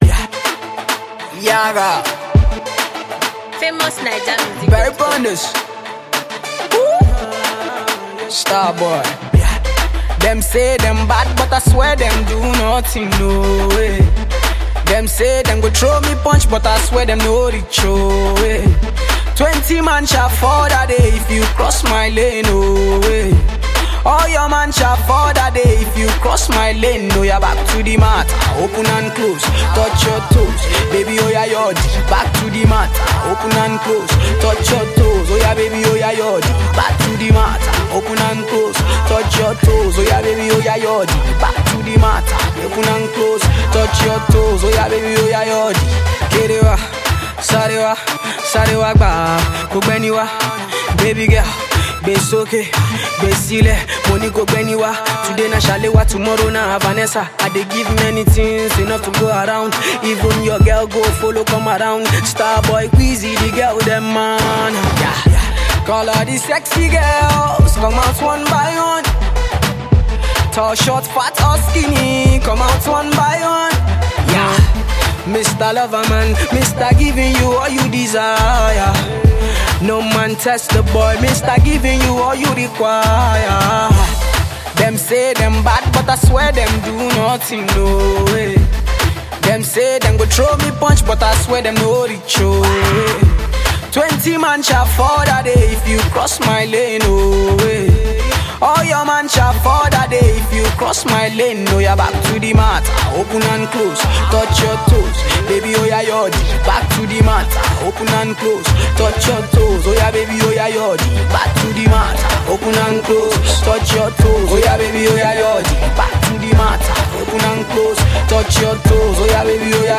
banging melody